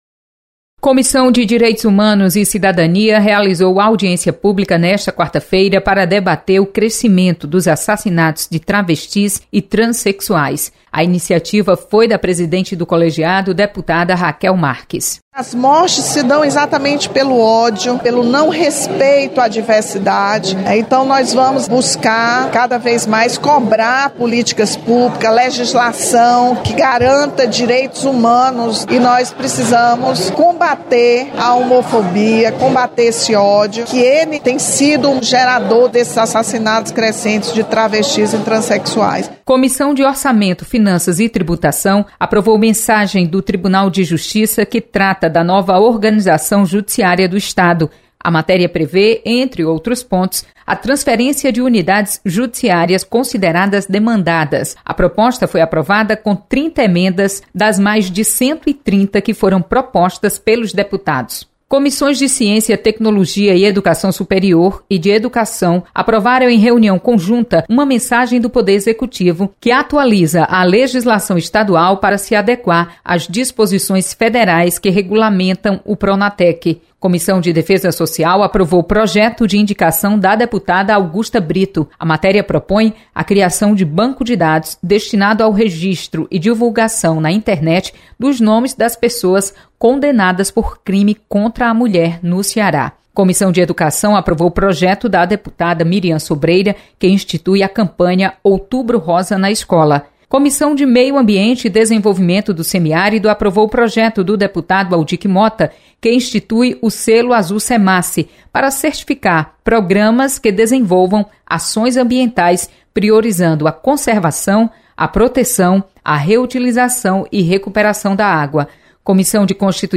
Acompanhe resumo das comissões técnicas permanentes da Assembleia Legislativa. Repórter